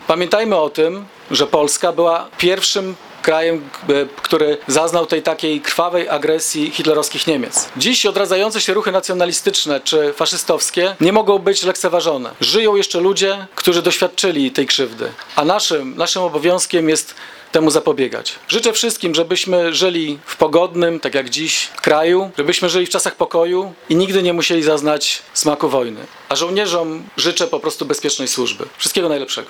Mieszkańcy Giżycka upamiętnili 79. rocznicę wybuchu II Wojny Światowej.
– 1 września 1939 roku, to jedna z najbardziej tragicznych dat w historii świata, pamiętajmy o tym – mówił Wojciech Iwaszkiewicz, burmistrz Giżycka.